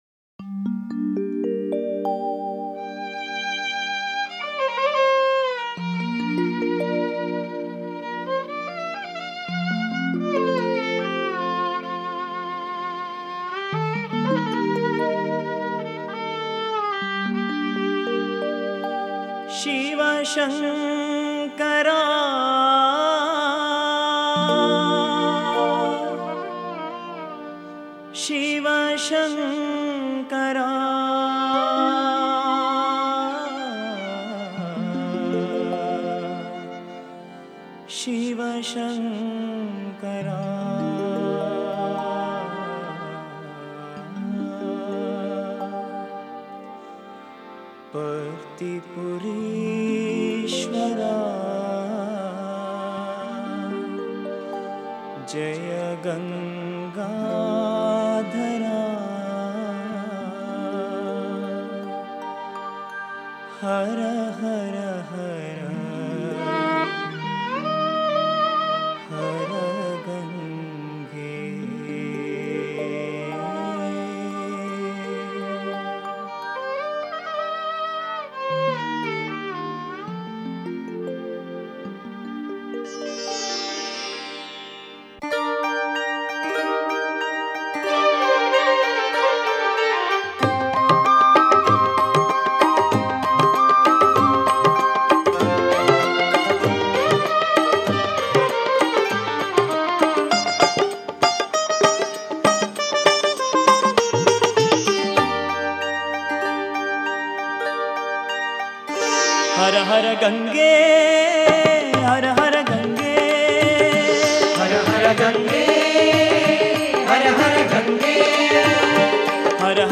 Author adminPosted on Categories Shiva Bhajans